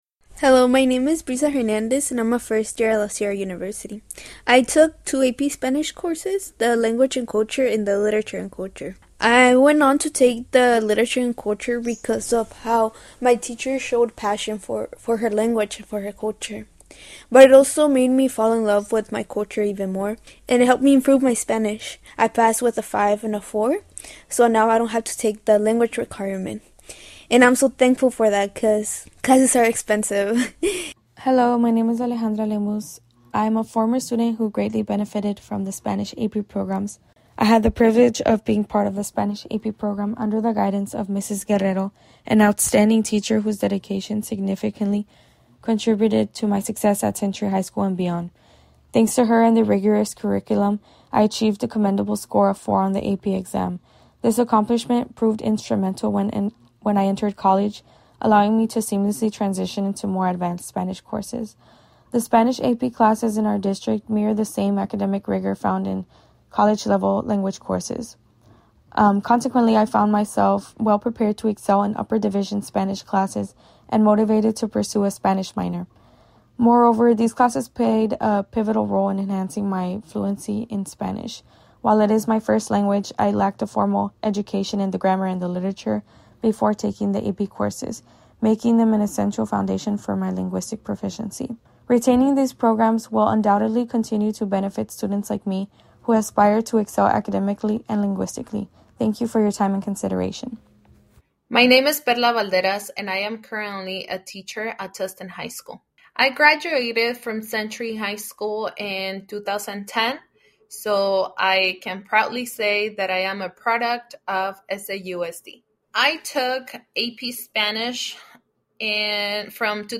grabación, estudiantes que ya se graduaron de Century te cuentan como les ayudó la clase en sus estudios universitarios y en sus carreras.